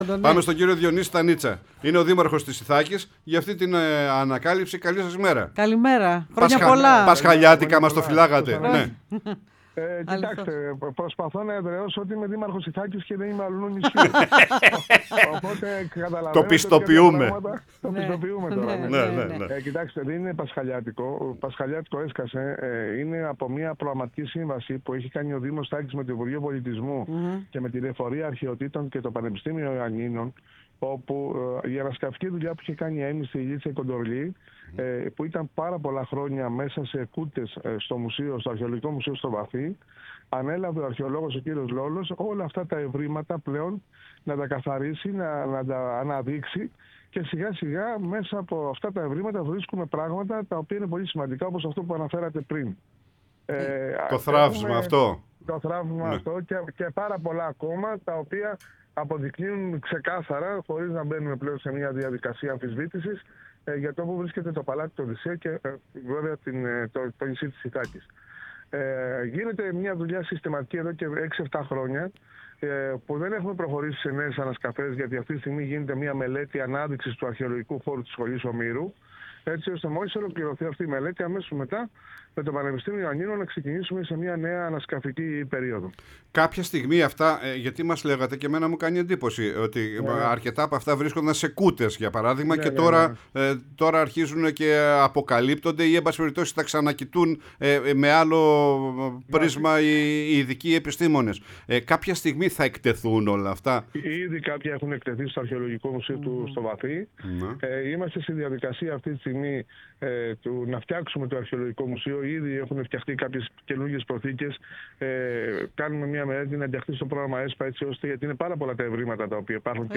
Ο Διονύσης Στανίτσας, Δήμαρχος Ιθάκης, μίλησε στην εκπομπή «Πρωινή Παρέα»